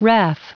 Prononciation du mot raff en anglais (fichier audio)
Prononciation du mot : raff